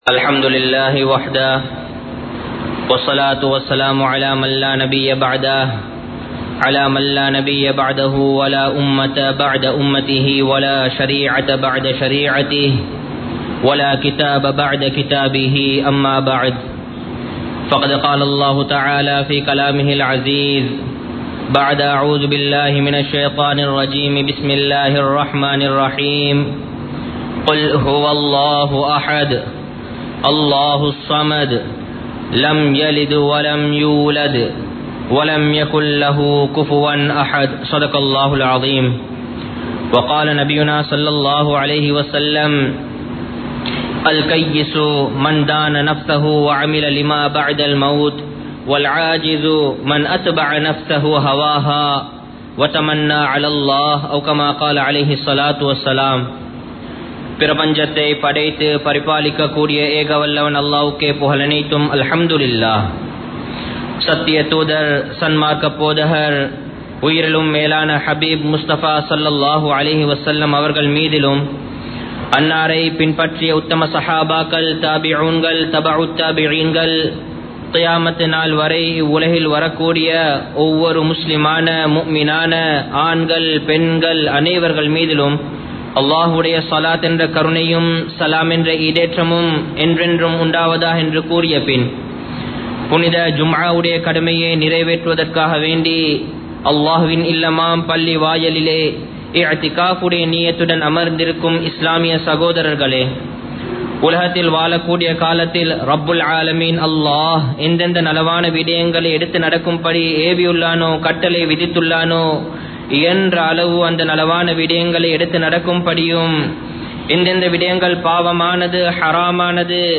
இஸ்லாம் கூறும் பொருளாதாரக் கொள்கை | Audio Bayans | All Ceylon Muslim Youth Community | Addalaichenai
Colombo 03, Kollupitty Jumua Masjith